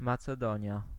Ääntäminen
Ääntäminen Tuntematon aksentti: IPA: /mat͡sɛˈdɔɲja/ Haettu sana löytyi näillä lähdekielillä: puola Käännös 1.